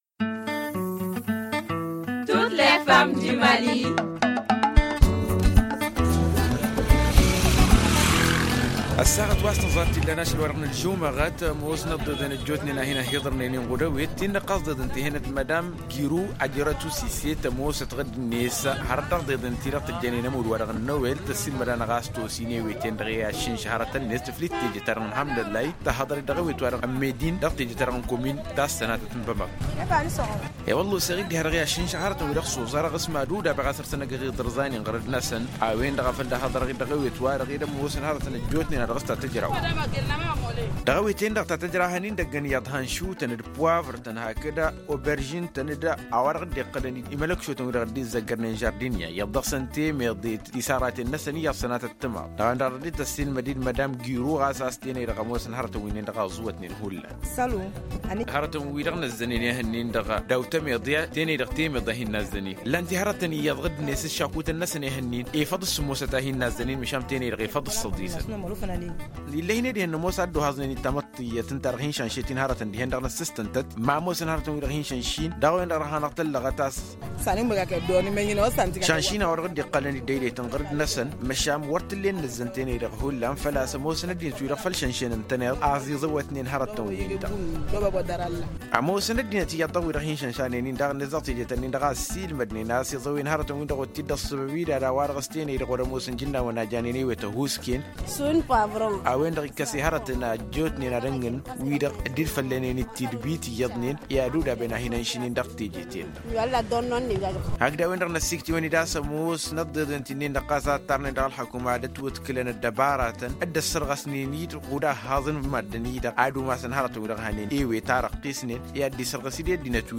Magazine en tamasheq: Télécharger